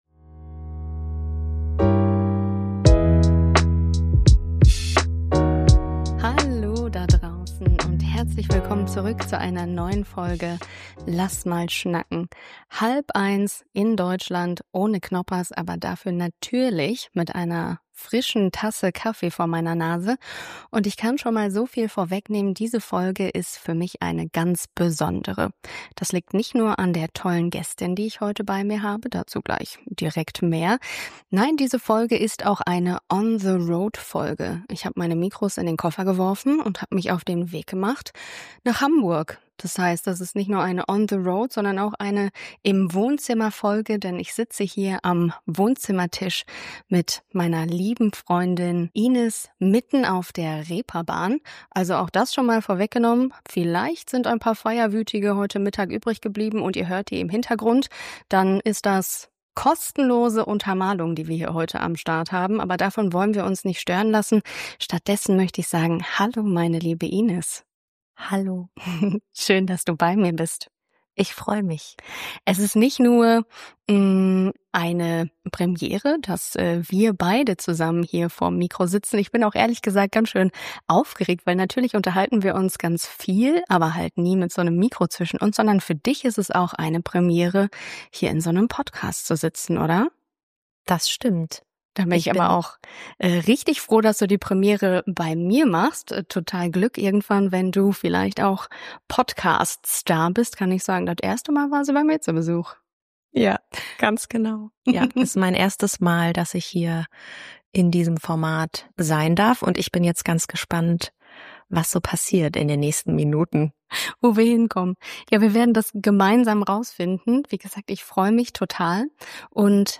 Wir sprechen über das, was uns gerade beschäftigt: das große Thema Sinn. Eine Folge wie ein Wohnzimmergespräch – ehrlich, ungefiltert, mit Platz für Zweifel, Mut und ein bisschen Lebenschaos.